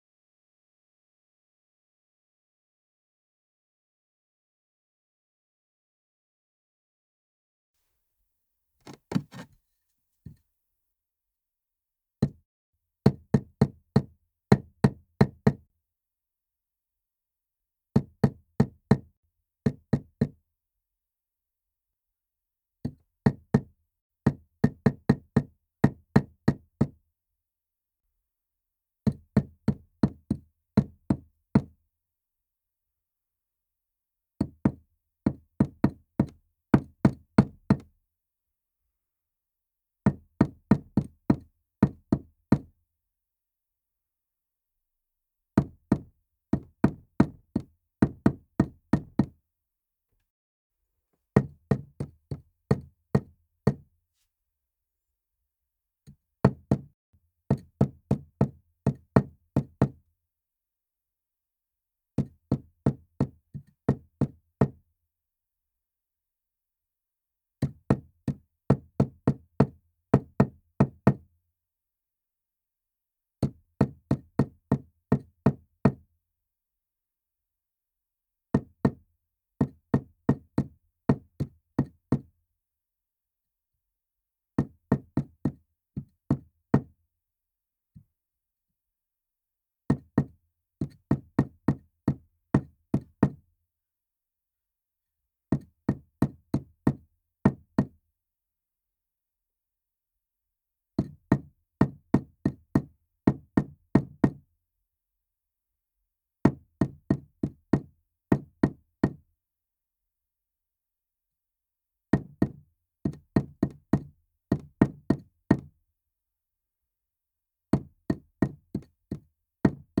NPC_Drill_02.ogg